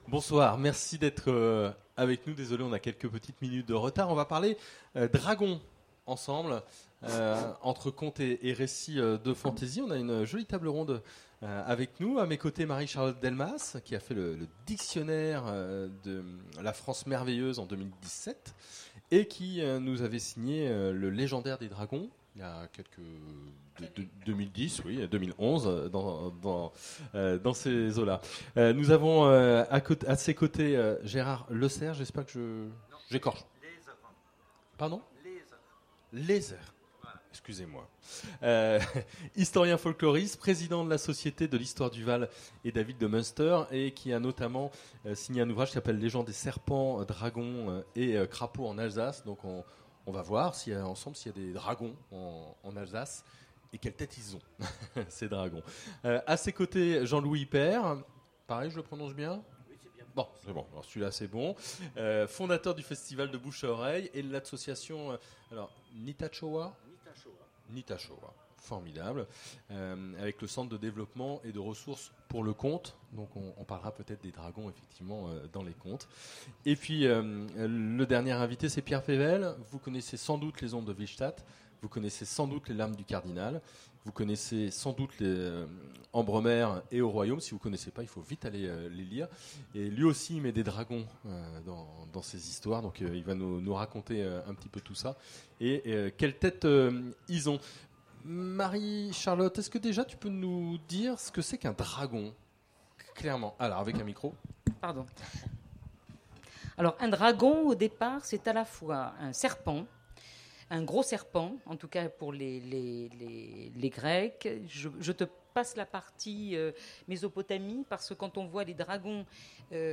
Mots-clés Dragons Conférence Partager cet article